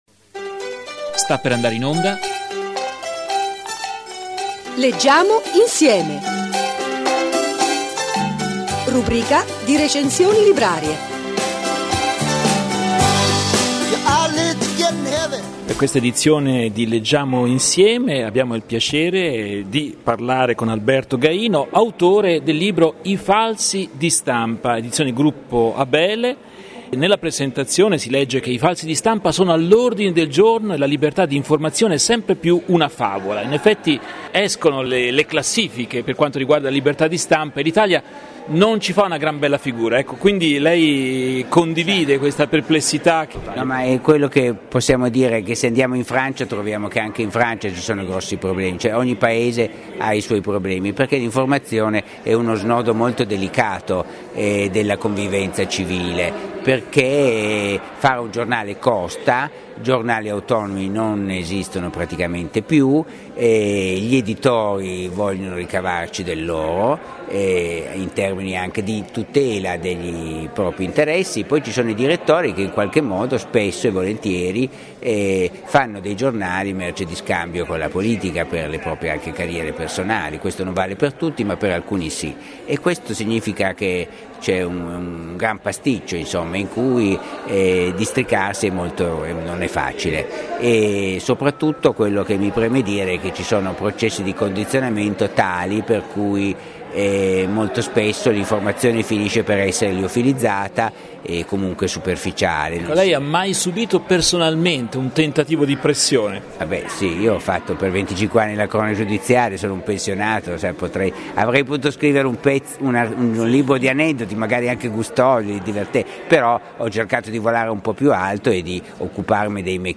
Radio Podcast